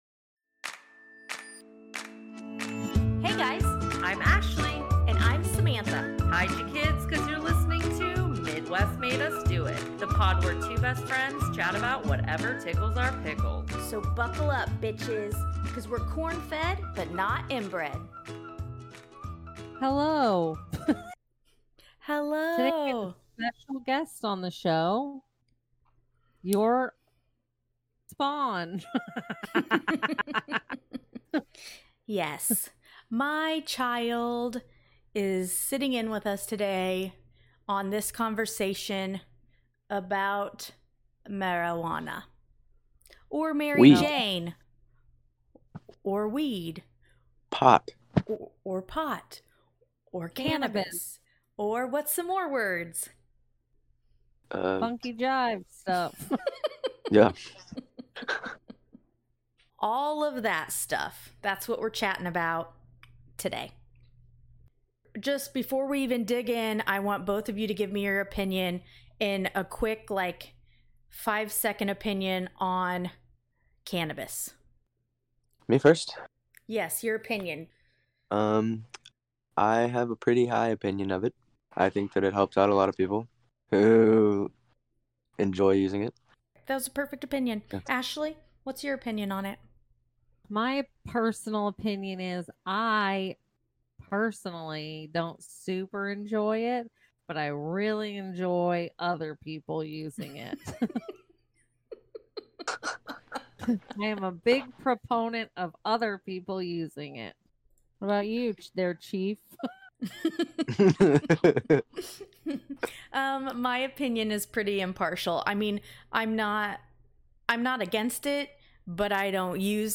The pod where two best friends chat about whatever tickles their pickle.